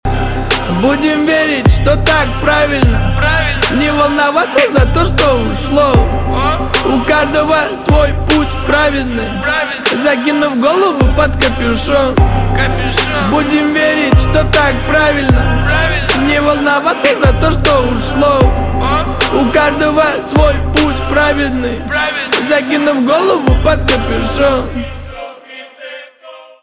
русский рэп
битовые , басы , хор